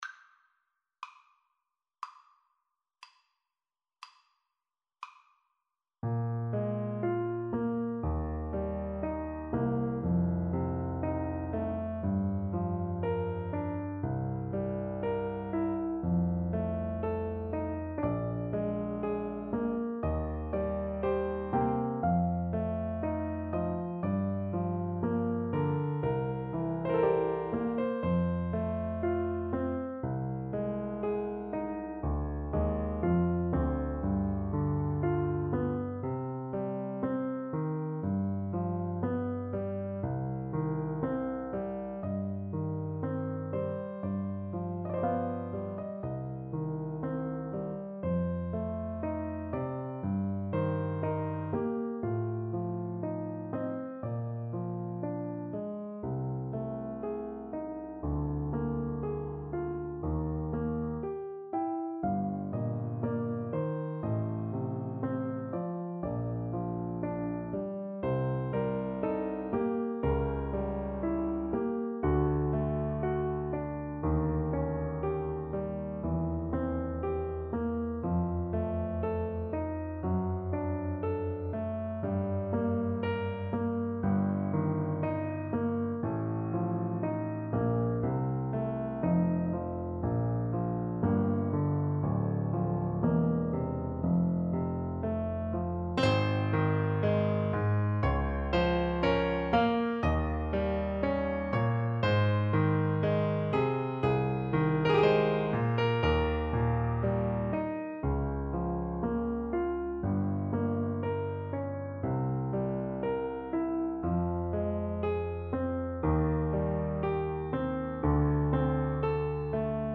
Play (or use space bar on your keyboard) Pause Music Playalong - Piano Accompaniment Playalong Band Accompaniment not yet available reset tempo print settings full screen
~ = 60 Largo
Bb major (Sounding Pitch) (View more Bb major Music for Flute )
Classical (View more Classical Flute Music)